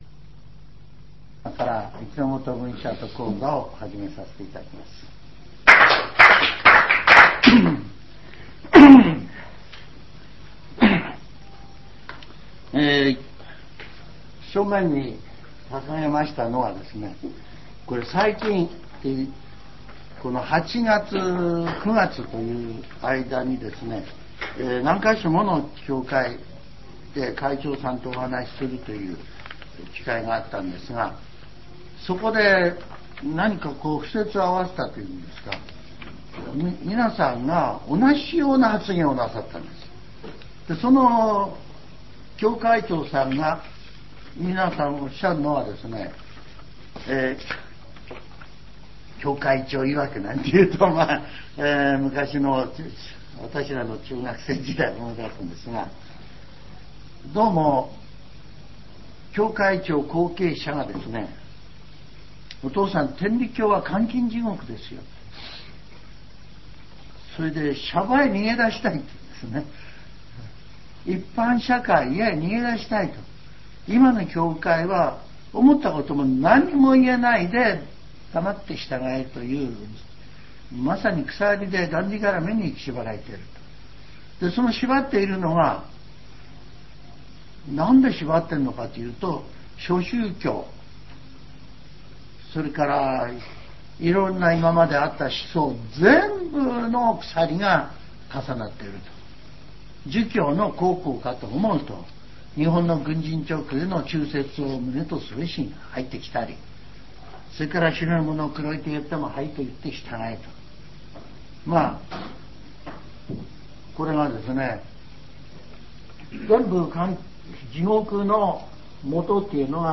全70曲中13曲目 ジャンル: Speech